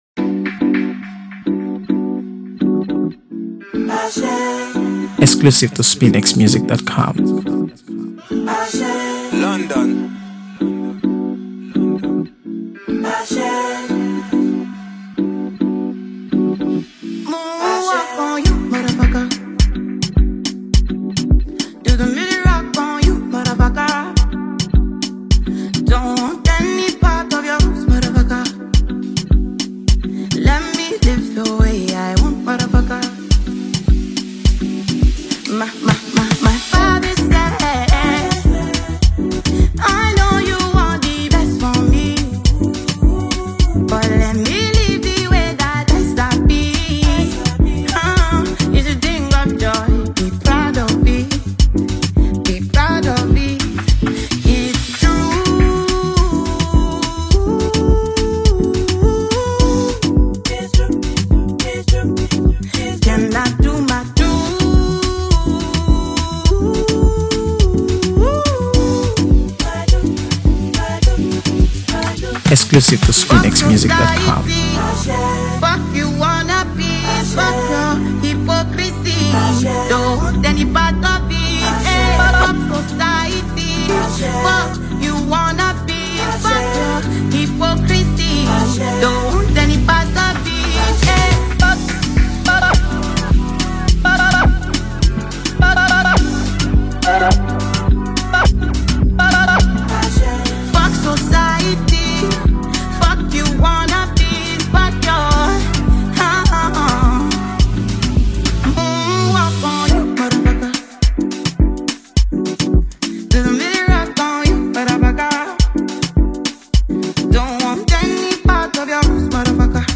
AfroBeats | AfroBeats songs
Renowned Nigerian singer-songwriter
is a rhythmic and entrancing dance track
With its infectious beats and captivating lyrics